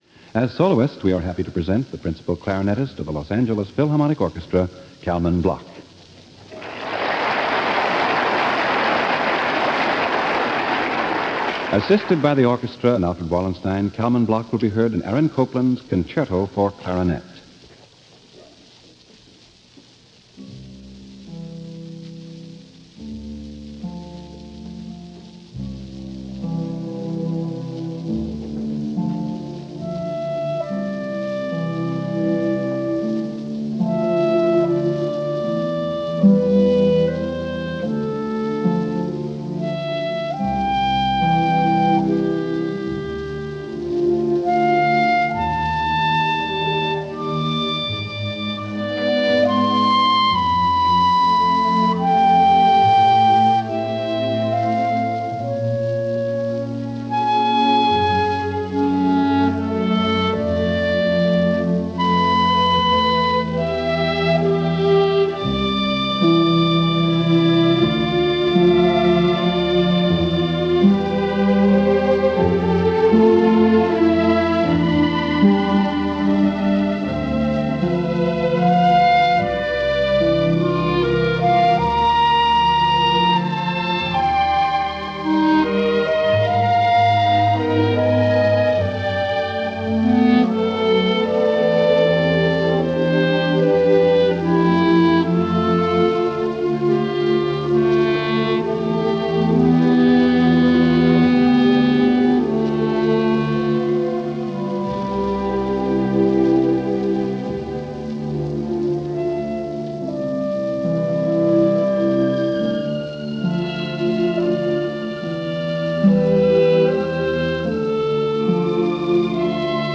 as performed in this broadcast
recorded in Ventura California on December 2, 1951.
lyrical and jazz-influenced